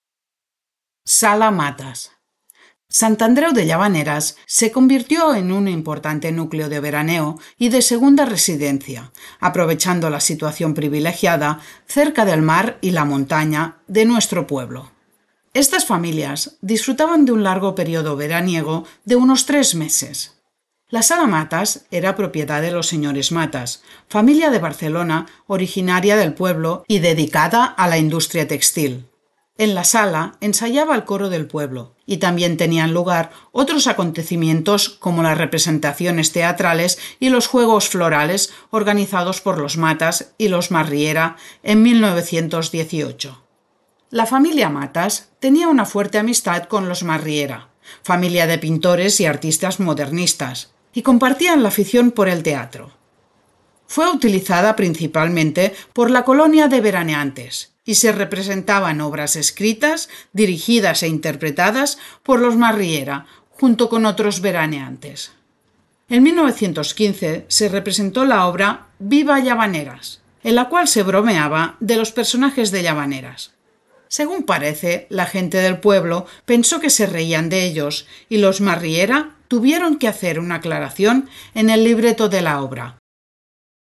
Ruta Modernista audioguiada